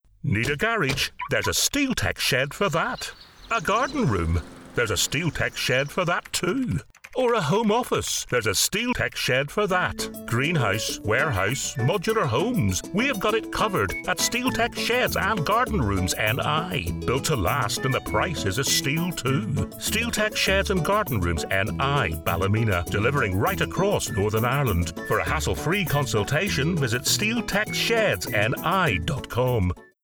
A Northern Irish Voice
Shed Commercial
I have a Northern Irish Accent which can be street or upperclass.